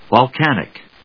音節vol・can・ic 発音記号・読み方
/vɑlkˈænɪk(米国英語), vɑ:ˈlkænɪk(英国英語)/